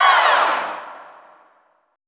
Index of /90_sSampleCDs/Best Service - Extended Classical Choir/Partition I/AHH FALLS
AHH LOWER -L.wav